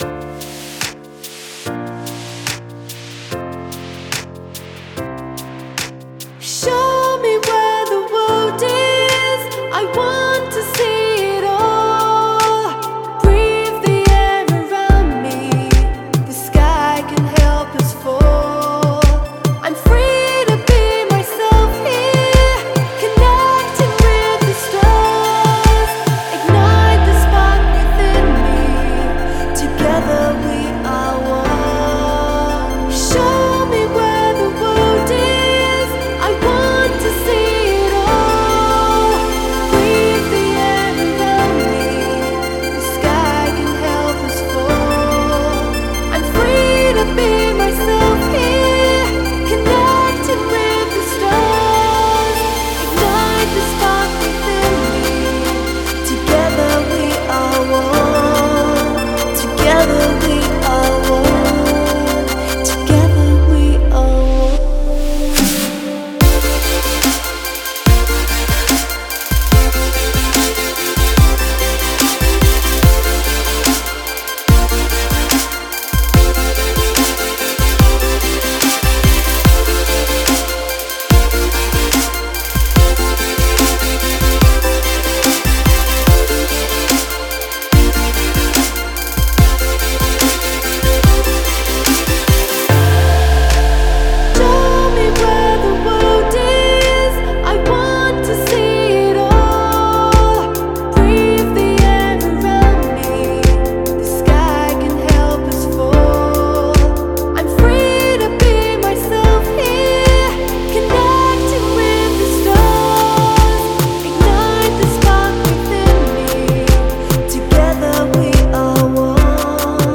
это яркая и эмоциональная композиция в жанре поп-рок